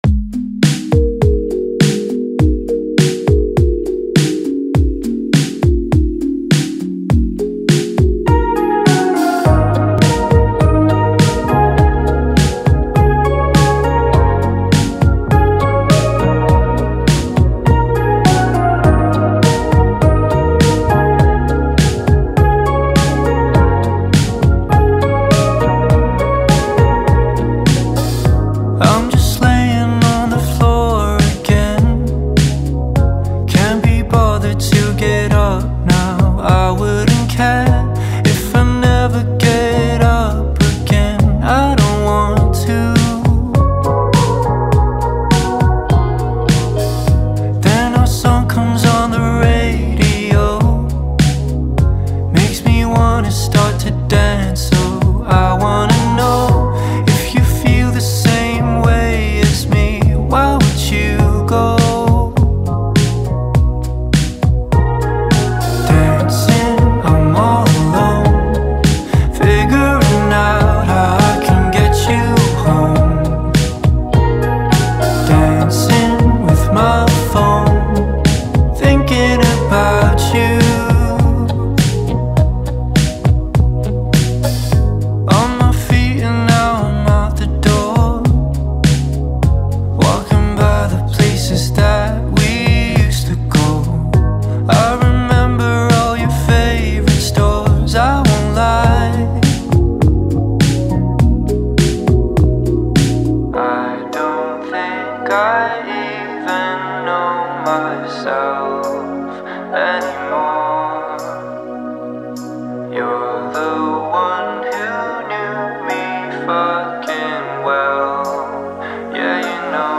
یک گروه موسیقی دو نفره